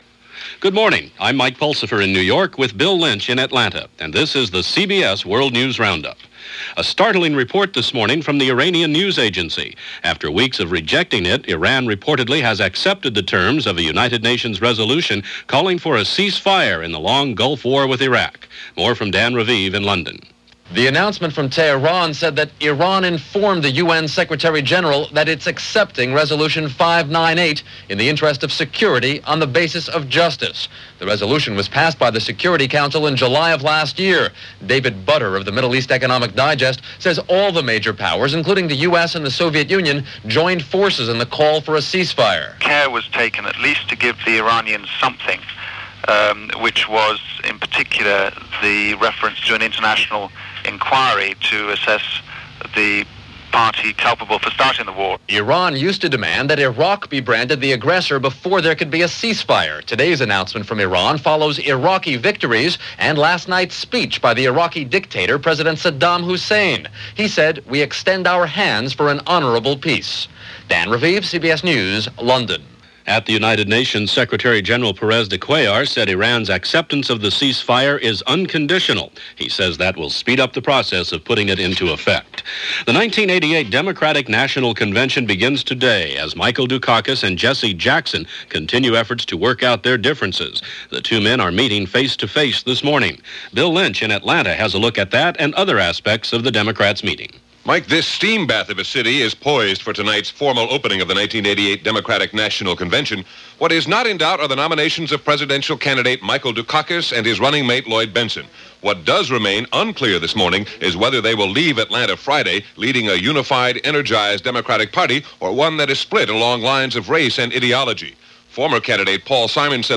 And that’s just a small slice of what went on, this July 18, 1988 as reported by The CBS World News Roundup.